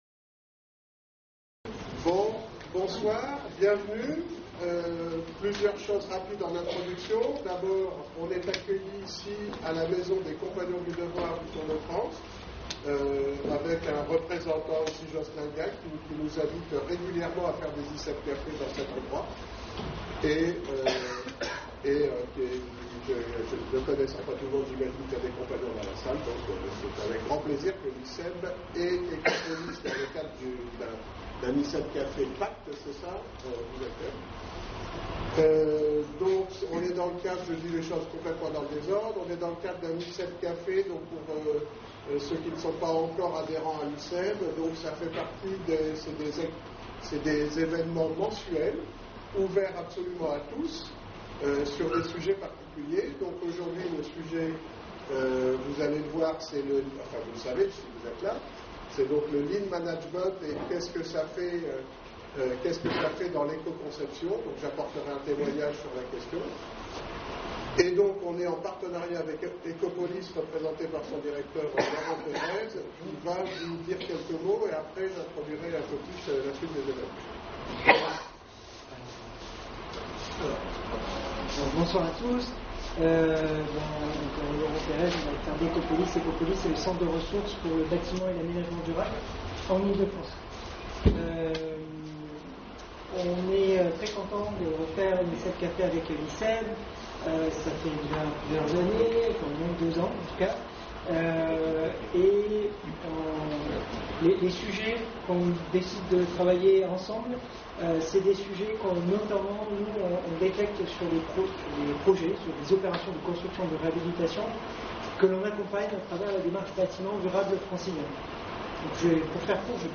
Maison des Compagnons du devoir,